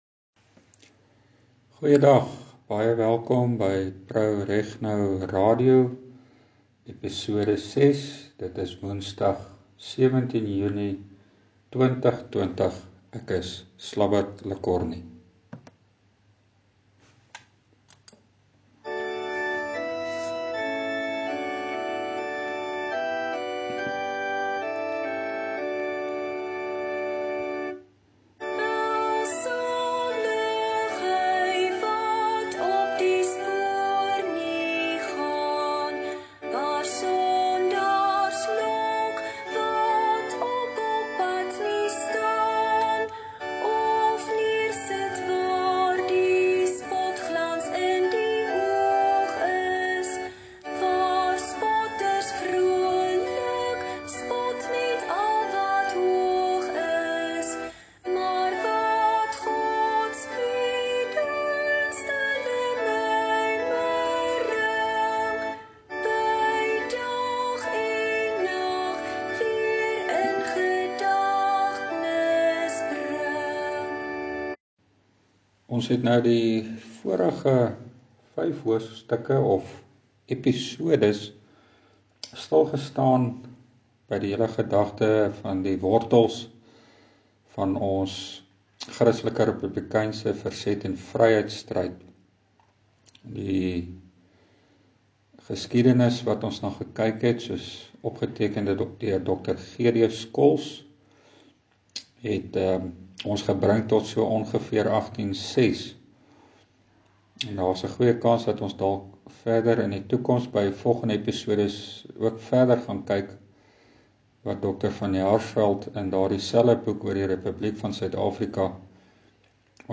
Inhoud van Episode 6: Voorlees en bespreking van Totius (dr. JD Du Toit) se artikel, Roeping en Toekoms , deel 1 Inleiding, oorsig, ‘roeping’ en ‘kerk’